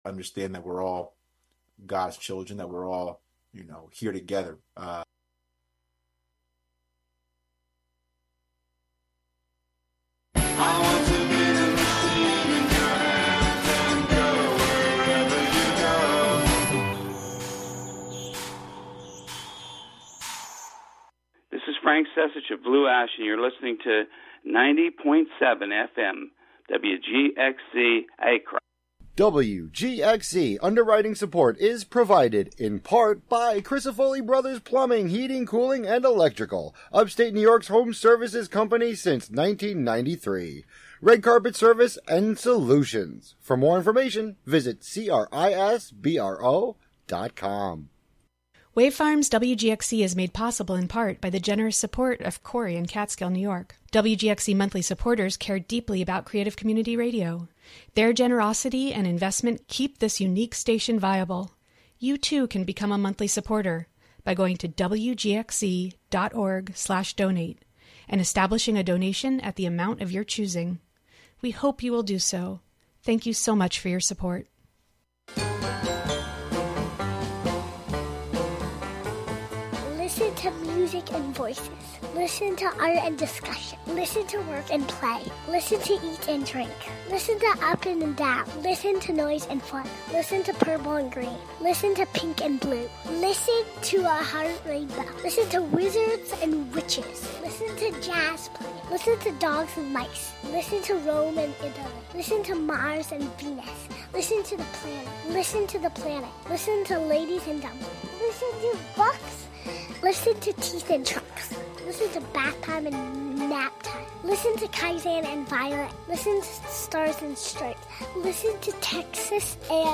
Live from the Catskill Makers space on W. Bridge St. in Catskill, a monthly show about science, technology, fixing, making, hacking, and breaking with the amorphous collection of brains comprising the "Skill Syndicate."